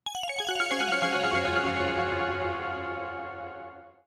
menu_button.mp3